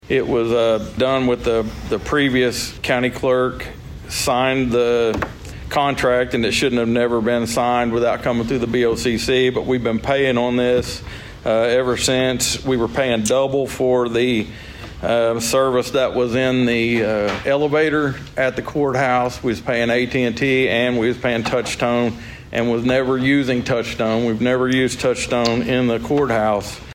District Two Commissioner Steve Talburt explains